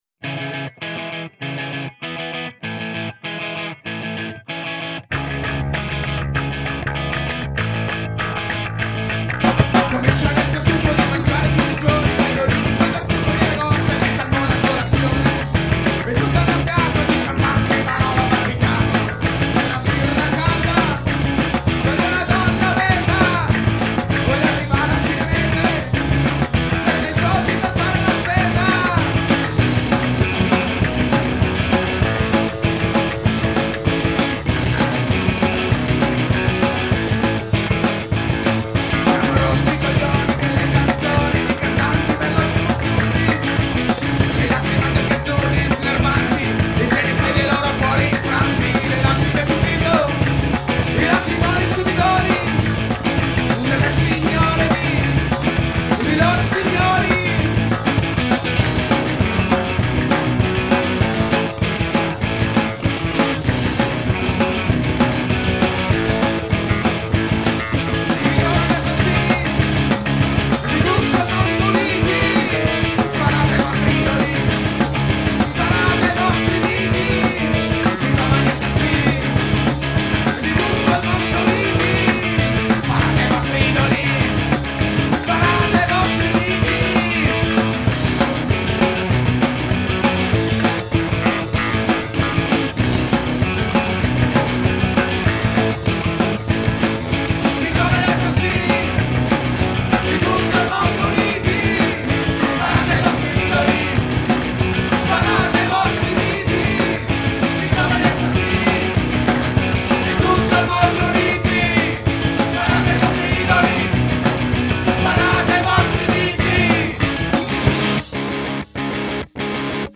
Ruvidi e grezzi, ma sempre con tante cose da dire.